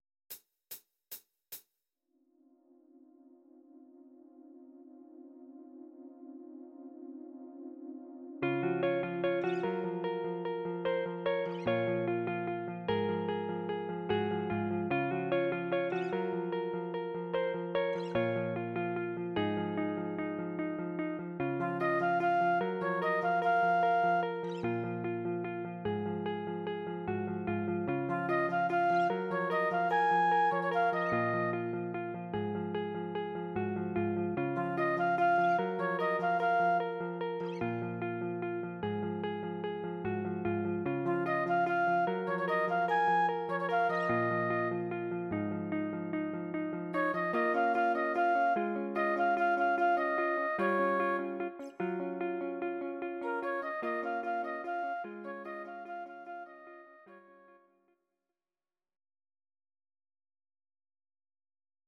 Audio Recordings based on Midi-files
Pop, 2010s